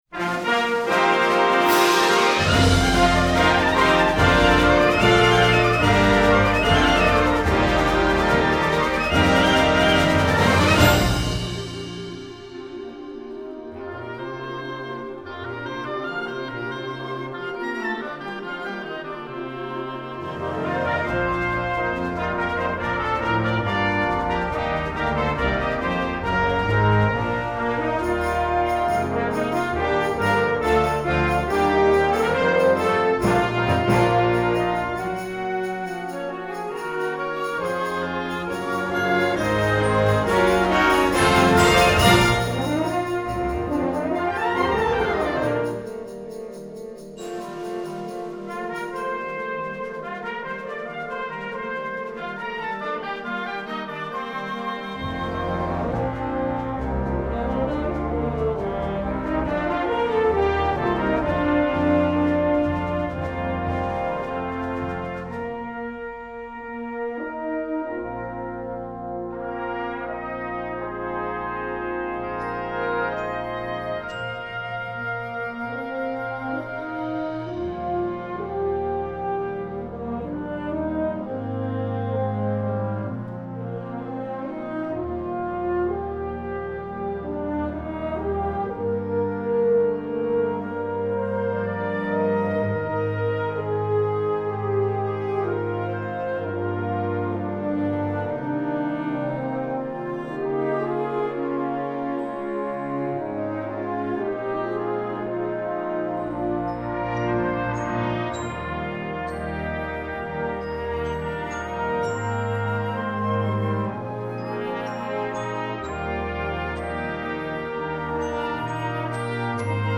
Gattung: Konzertstück
4:25 Minuten Besetzung: Blasorchester PDF